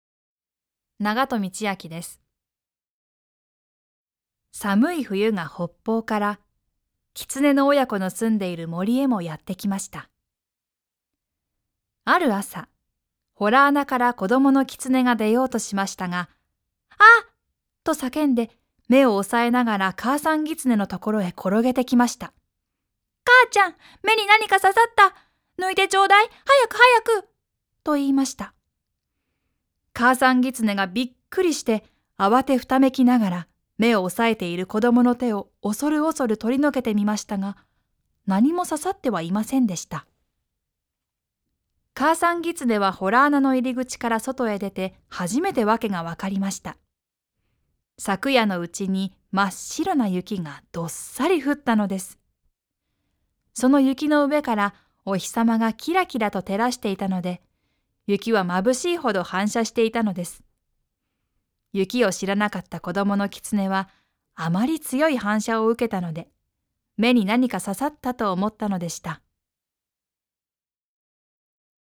ボイスサンプル
朗読『手ぶくろを買いに』